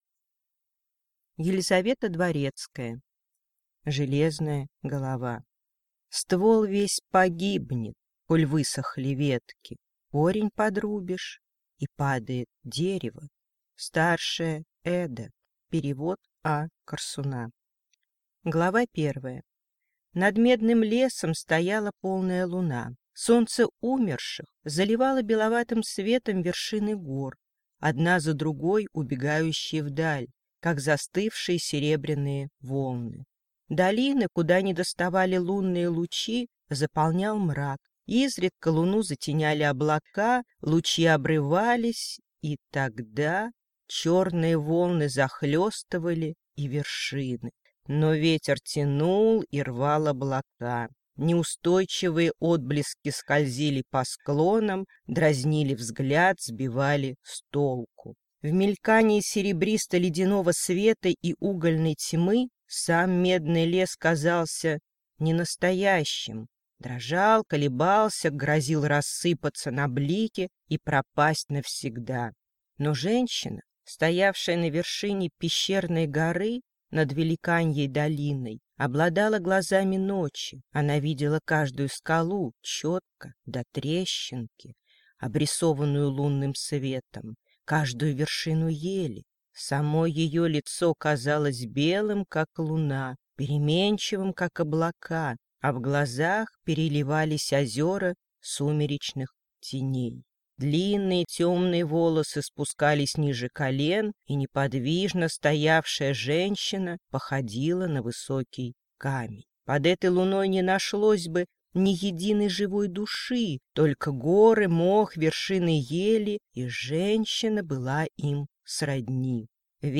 Аудиокнига Корни гор. Книга 1: Железная голова | Библиотека аудиокниг